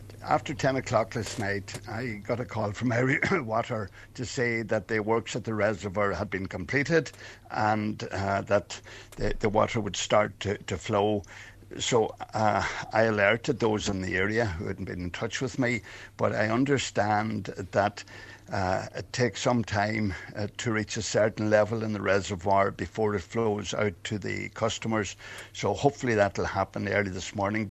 He says that the problem has been fixed, and it is now a matter of waiting for the reservoir to replenish: